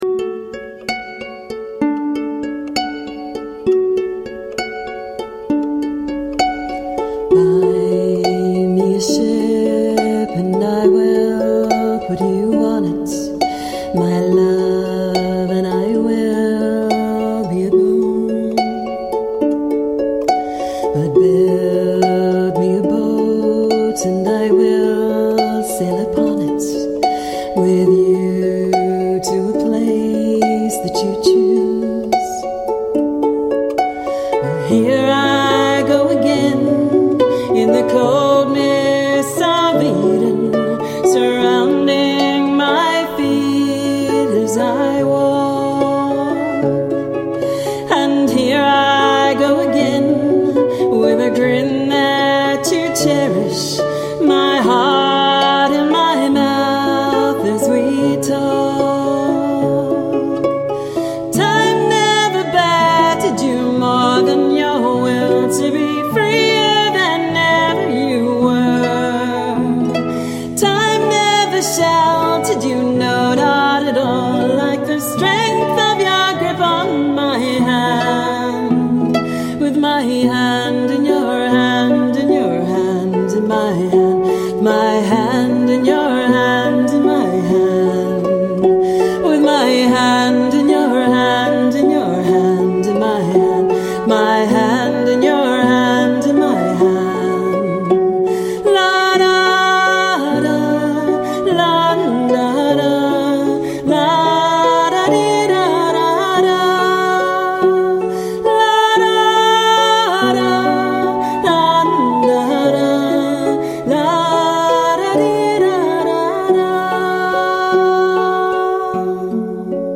Vocals & Celtic Harp
Flute, Low D Whistle, Tin Whistle, Bodhran, Shakers, Vocals
Viola & Cello